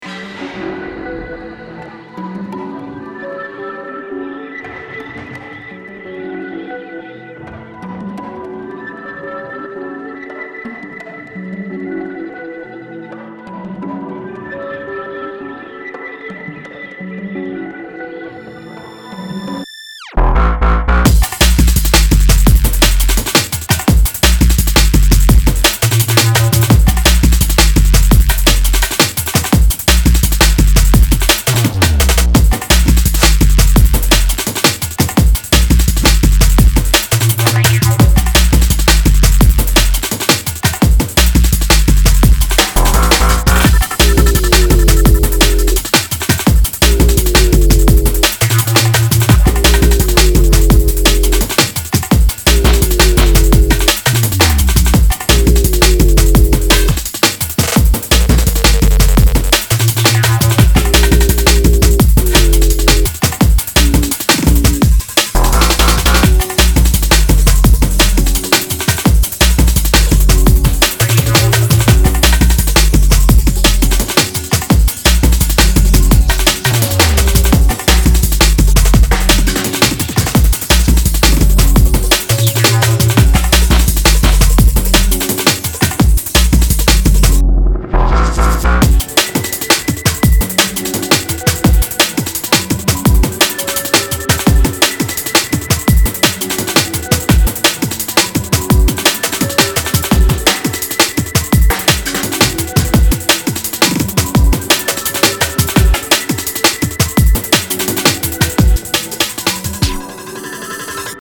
Taking us on a journey of jungle drums and break-beat sonics